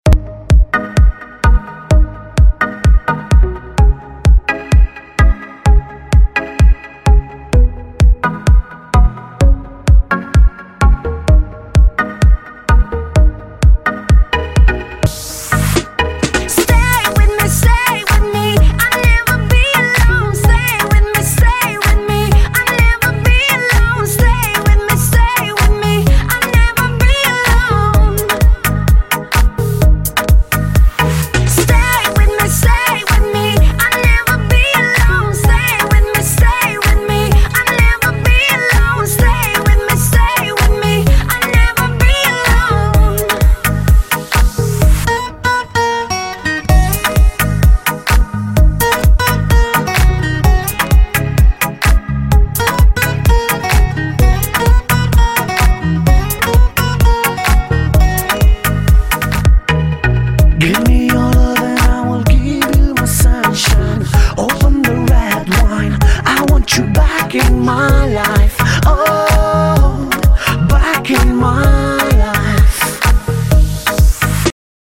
• Качество: 256, Stereo
поп